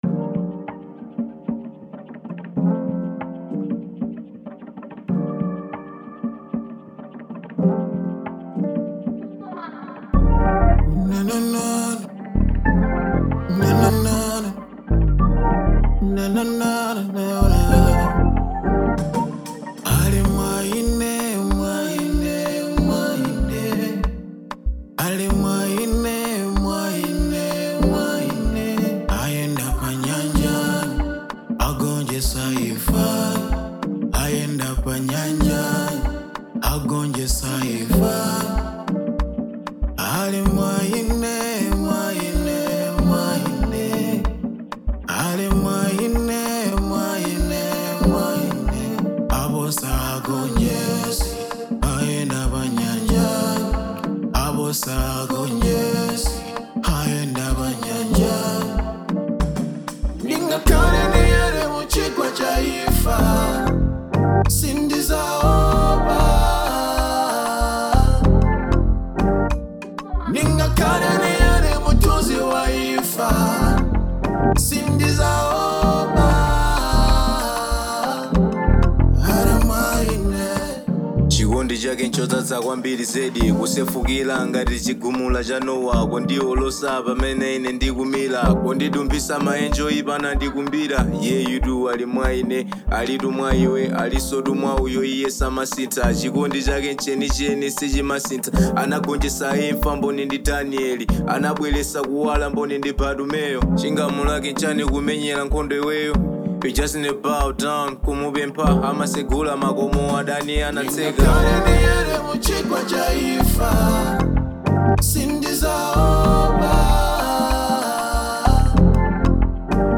Genre : Gospel